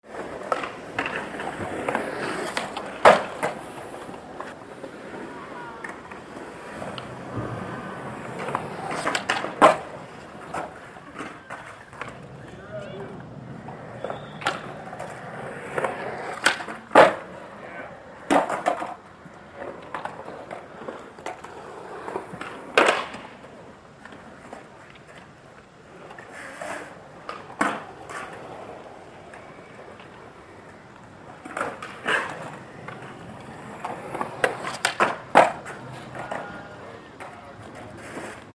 Звуки скейтборда
Звуки скейт-парка с катающимися подростками